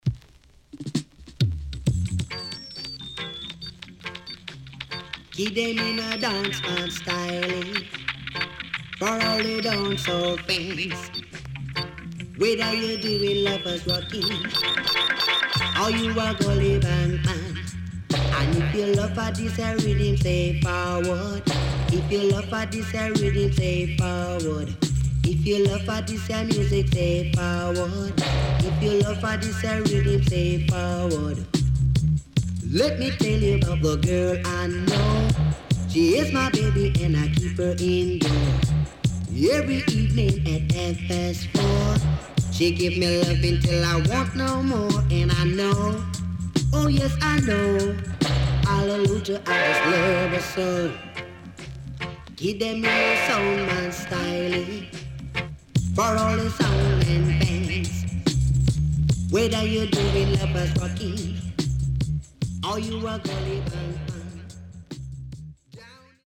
HOME > LP [DANCEHALL]
SIDE A:少しチリノイズ入りますが良好です。
SIDE B:少しチリノイズ入りますが良好です。